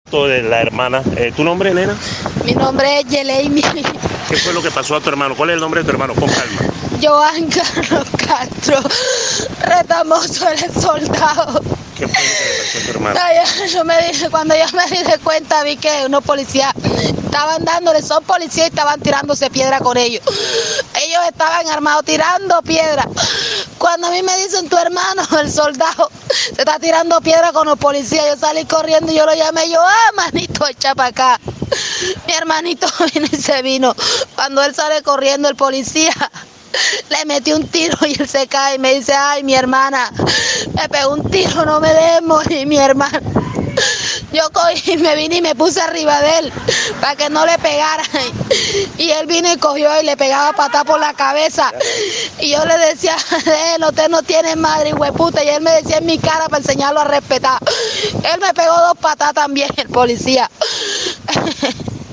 Yo cogí y me puse arriba de él pa que no le pegaran”, dijo la mujer en medio del llanto.